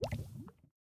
drip_lava_cauldron3.ogg